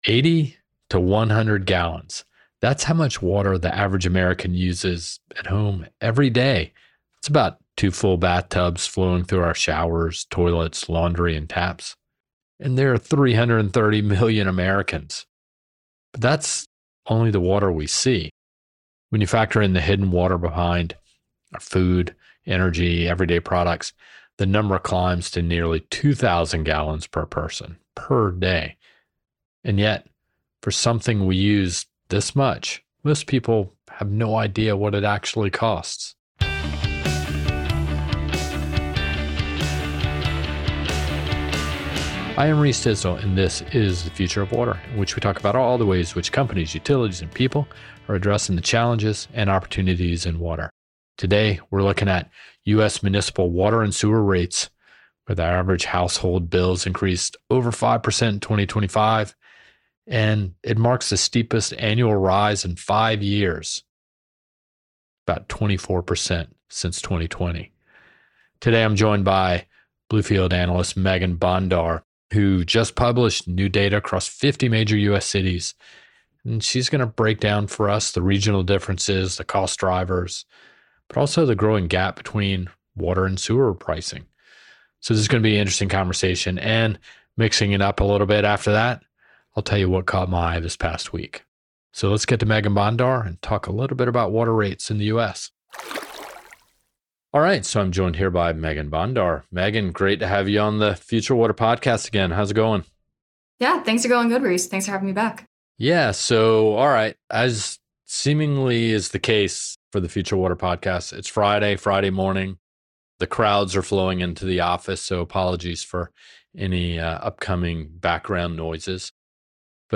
The conversation examines why water and wastewater rates are moving at different speeds, how regional factors shape what households pay, and what structural forces are locking in higher costs for the long term.